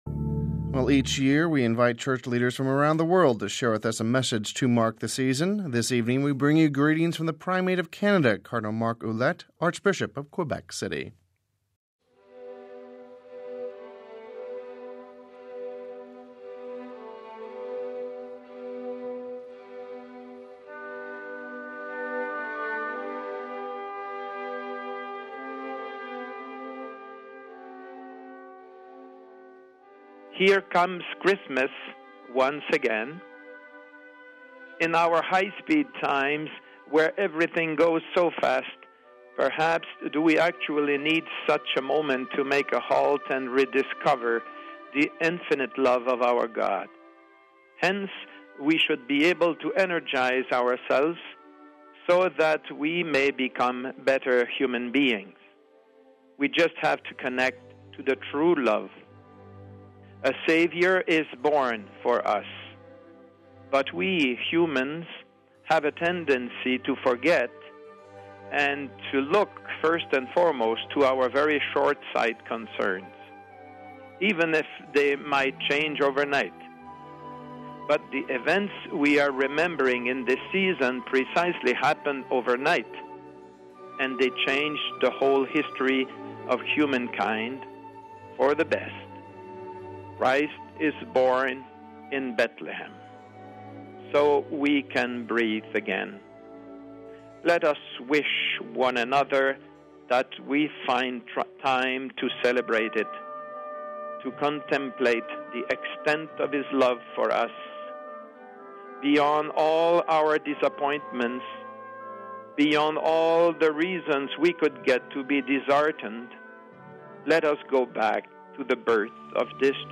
Christmas Message From Cardinal Marc Ouellet